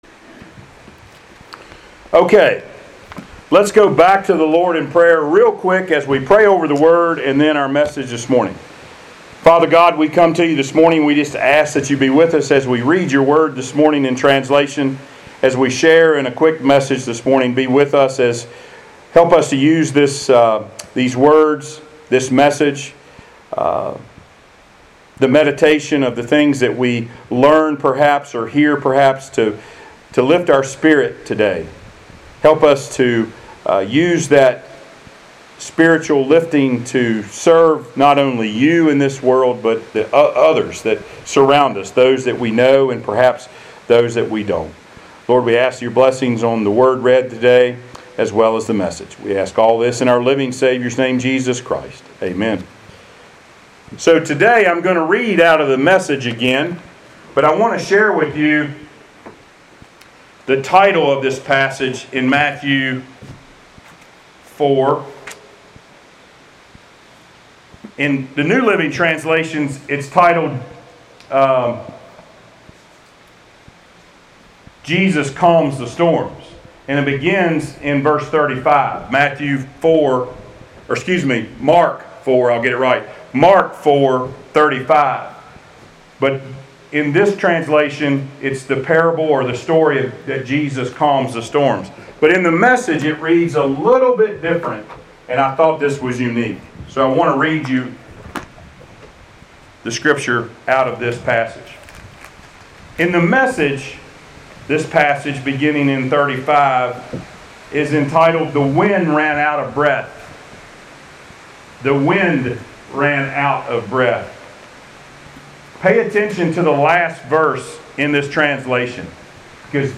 Passage: Mark 4:35-40 Service Type: Sunday Worship